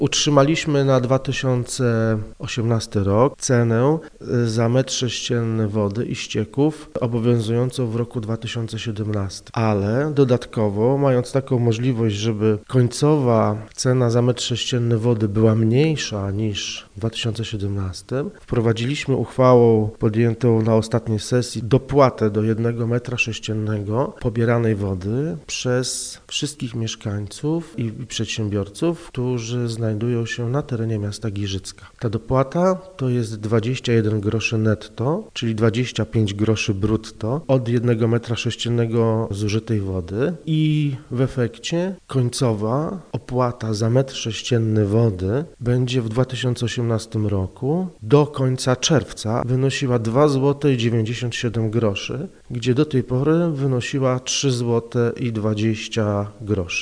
Reporter Radia 5 pytał o to Cezarego Piórkowskiego, przewodniczącego Rady Miejskiej w Giżycku.